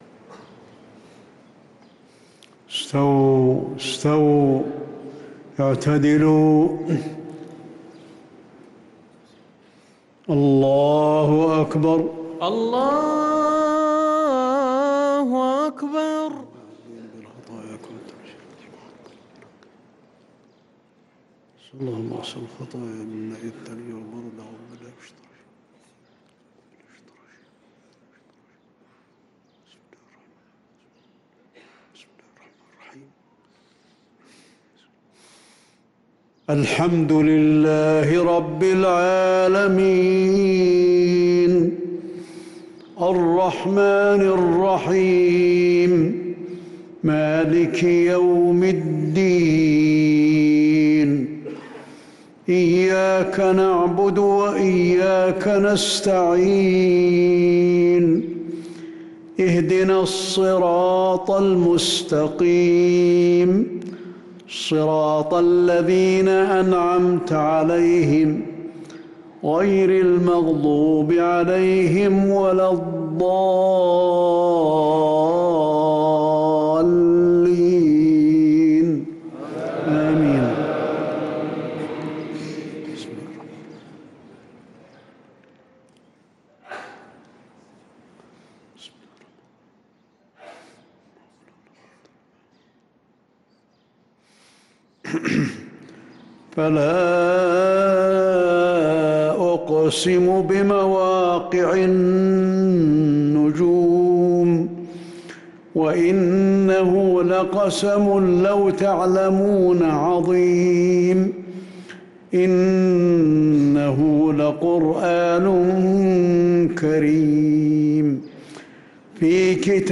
صلاة العشاء للقارئ علي الحذيفي 9 شعبان 1445 هـ
تِلَاوَات الْحَرَمَيْن .